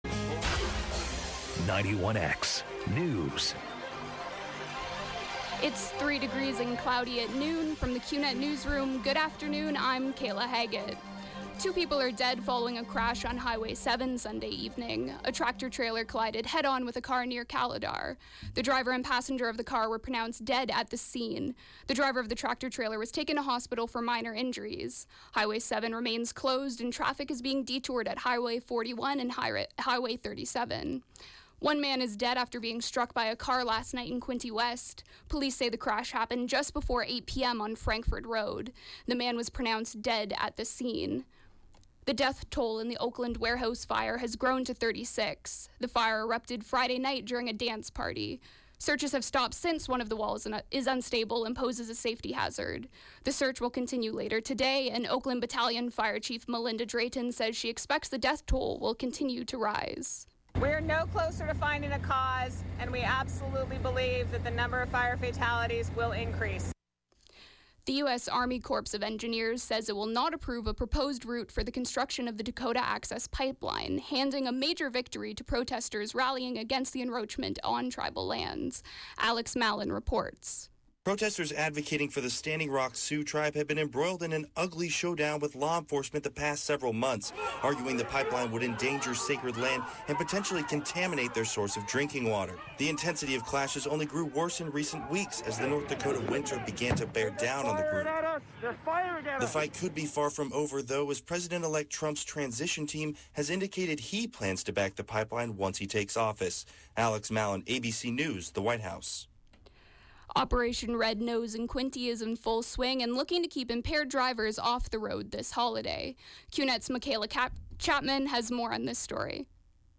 91X FM Newscast – Monday, Dec. 5, 2016, 12 p.m.